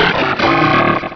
Cri de Colhomard dans Pokémon Rubis et Saphir.